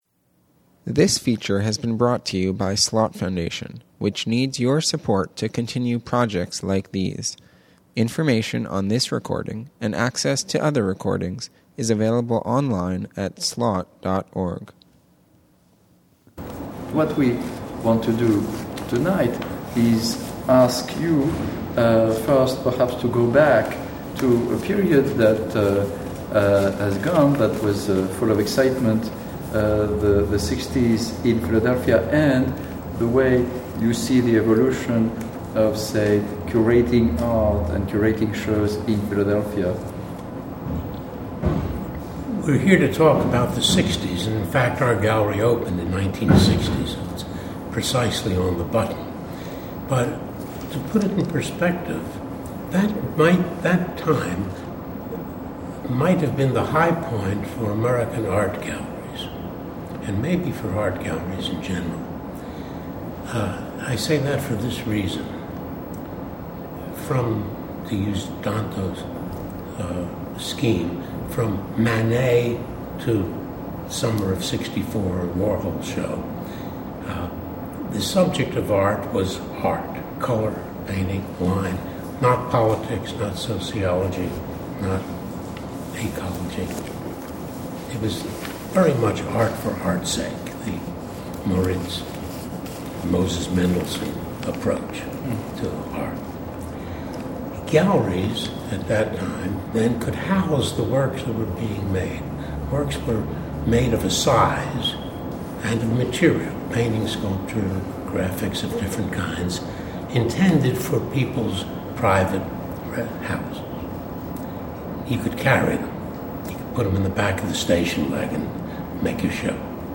a public conversation